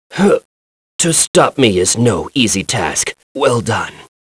Lusikiel-Vox_Dead.wav